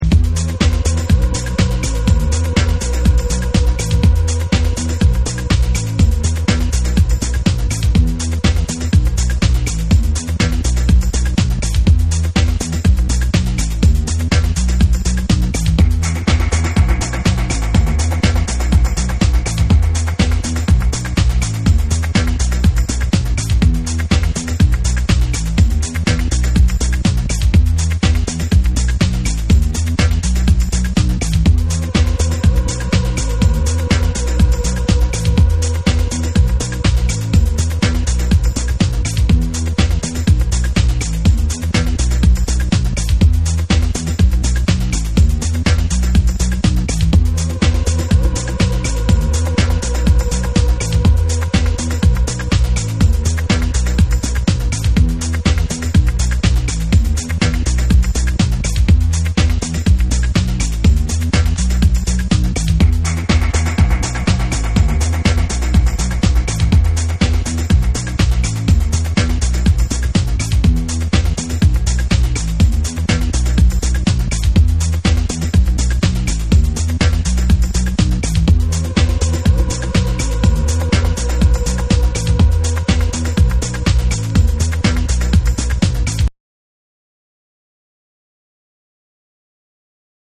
TECHNO & HOUSE / REGGAE & DUB